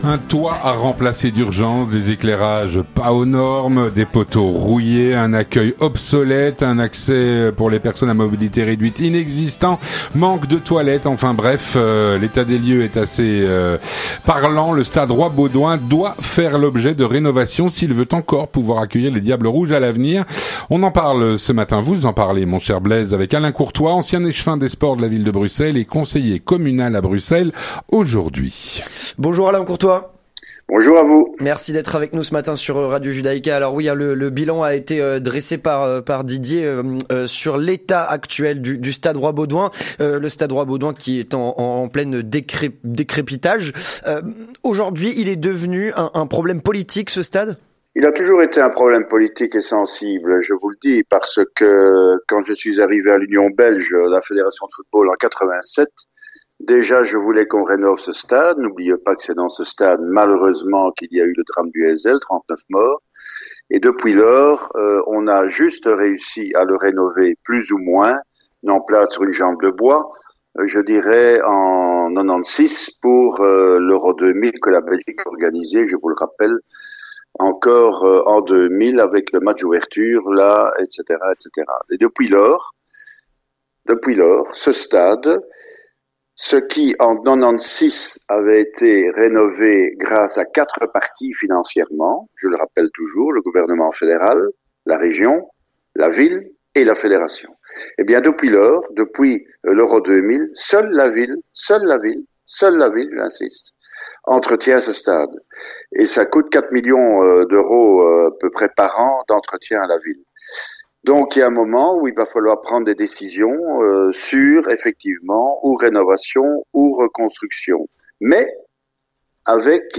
on fait le point avec Alain Courtois, ancien échevin des Sports de la Ville de Bruxelles, conseiller communal à Bruxelles aujourd’hui.